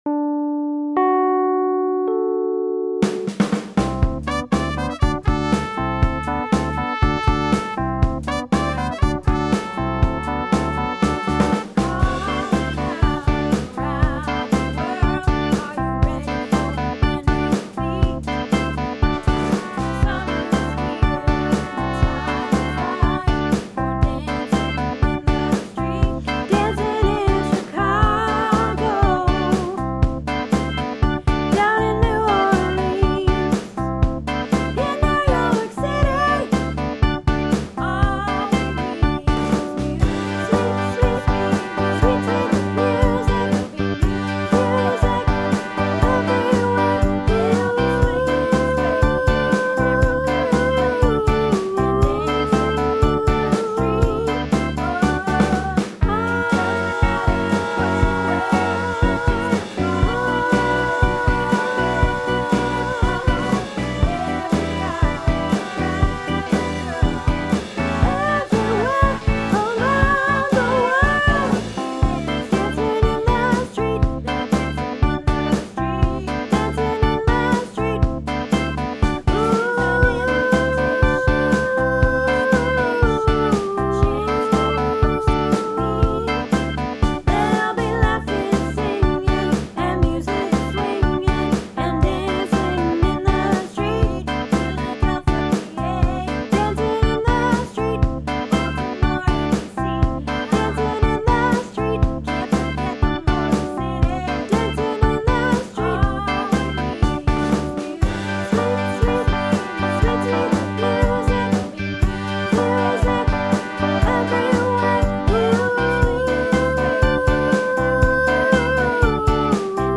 Dancing in the Street* - Soprano